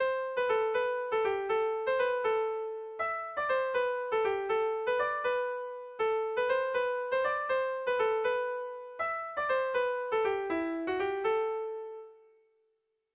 Tragikoa
ABD